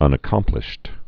(ŭnə-kŏmplĭsht)